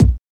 Mid Heavy Kick Sound B Key 532.wav
Royality free kick drum single shot tuned to the B note. Loudest frequency: 265Hz
mid-heavy-kick-sound-b-key-532-nir.mp3